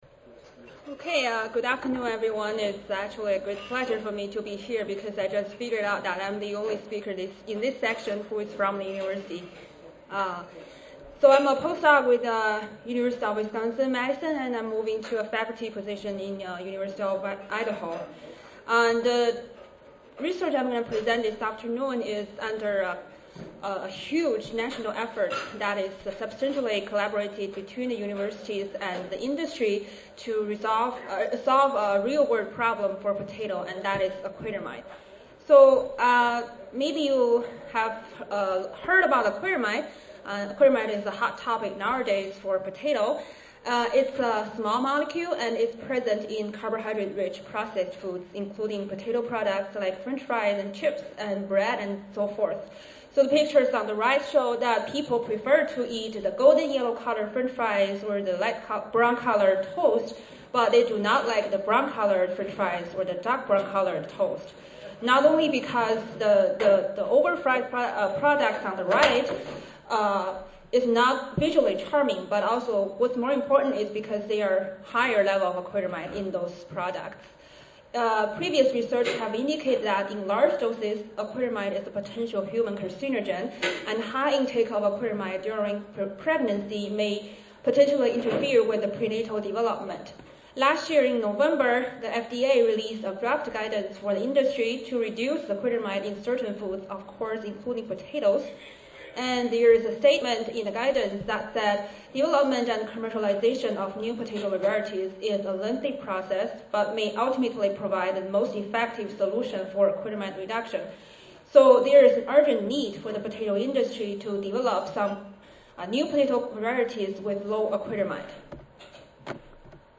AIS Consulting Audio File Recorded Presentation